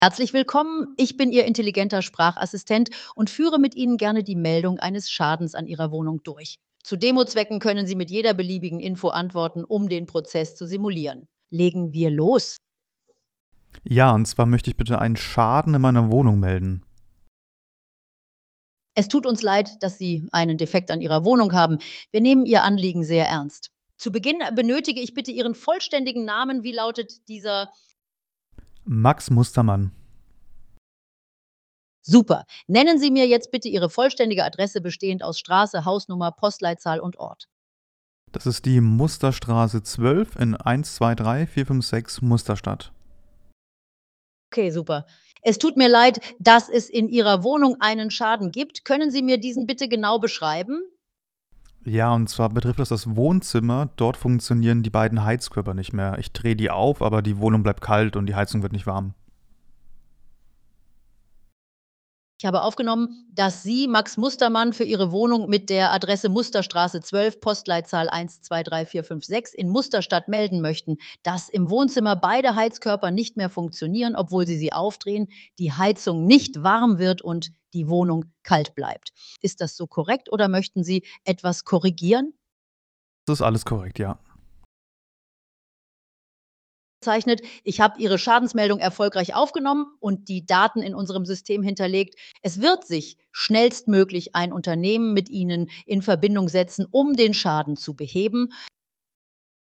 Exzellenter Service ist hörbar.Unsere Voice-Demos:
Überzeugen Sie sich von der natürlichen Sprache und der intelligenten Reaktionsfähigkeit unserer Voice-Agenten.
Der Mieter meldet einen Schaden in seiner Wohnung. Der AI Agent klassifiziert das Anliegen und vereinbart einen Termin mit den Handwerkern.